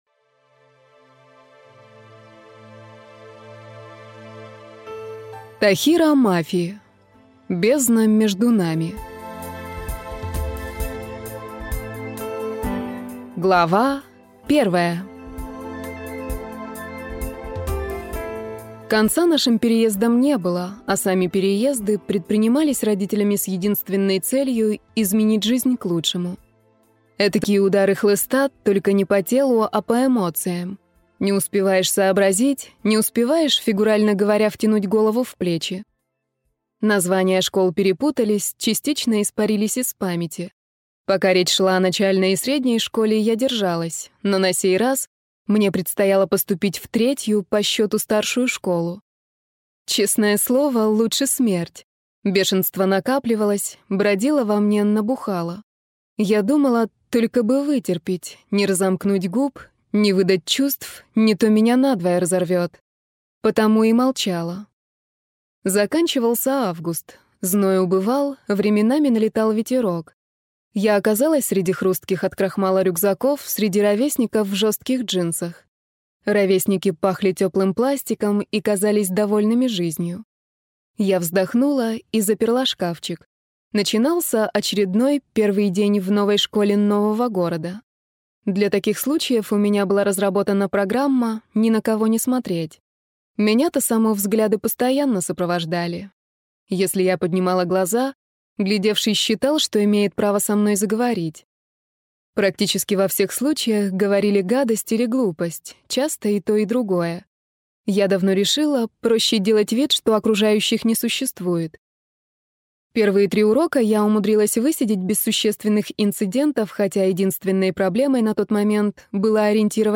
Аудиокнига Бездна между нами | Библиотека аудиокниг